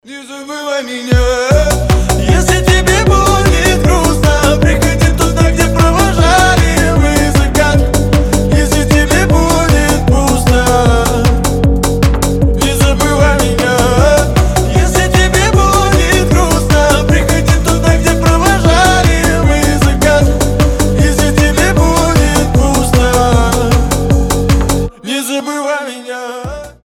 позитивные
мужской голос
дуэт
быстрые